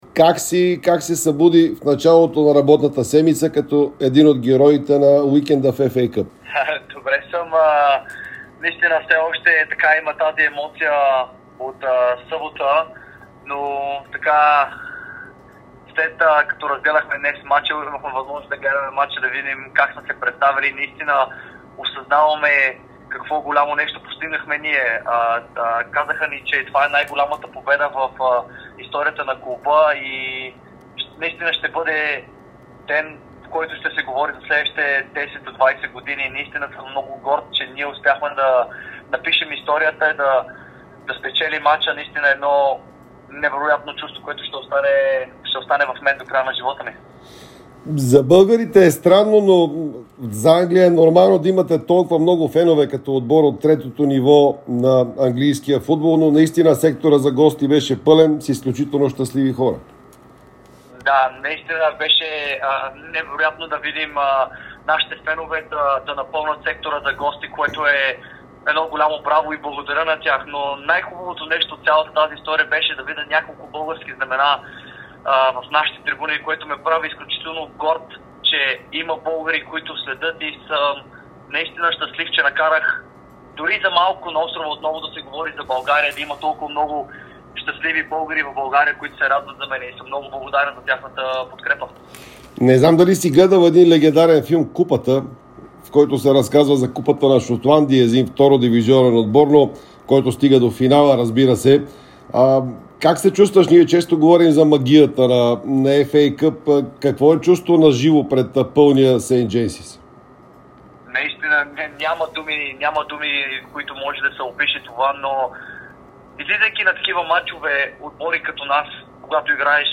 Българският вратар Димитър Митов, който се превърна в герой за клубния си тим Кеймбридж при отстраняването на Нюкасъл от ФА Къп, даде специално интервю за Дарик радио и dsport.